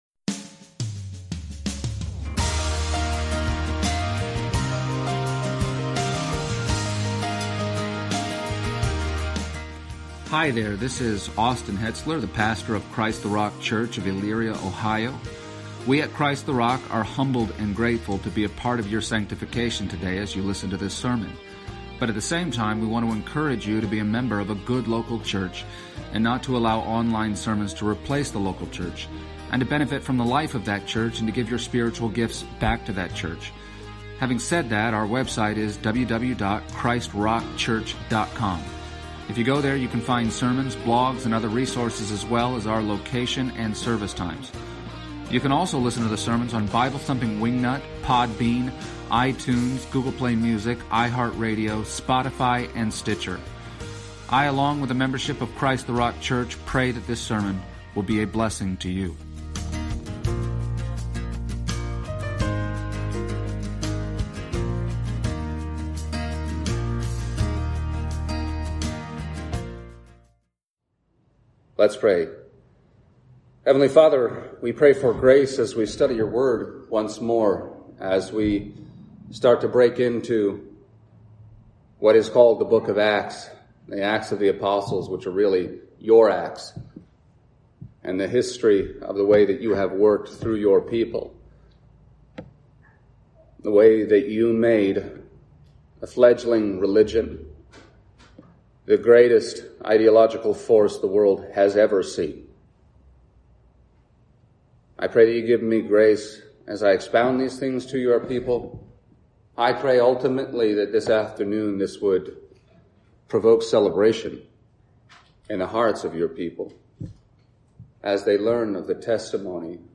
Series: Exposition of the Book of Acts Service Type: Sunday Morning %todo_render% « Man of God